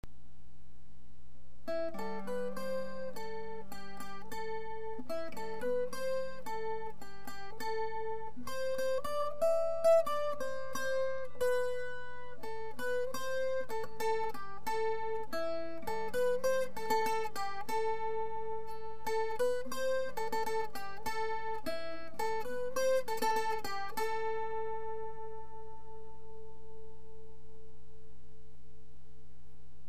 Traditionnels